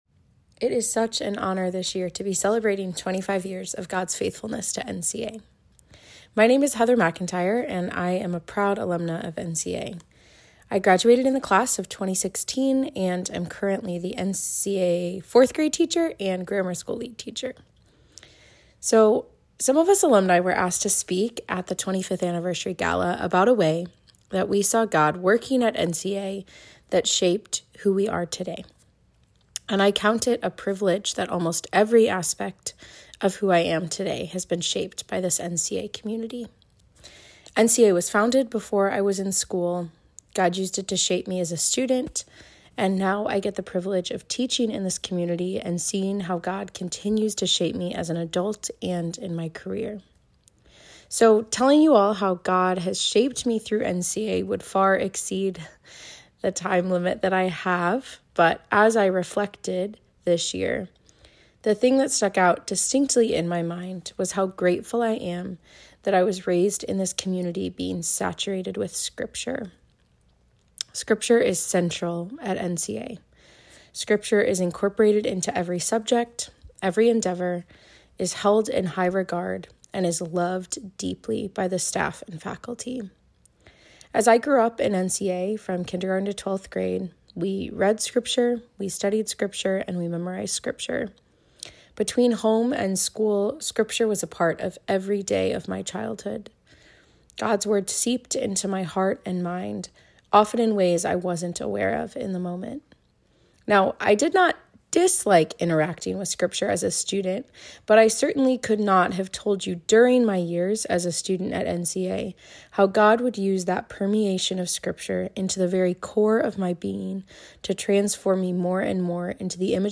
Listen below to speeches given at NCA’s 25th Year Celebration Gala about how these alumni were shaped by their NCA education and teachers.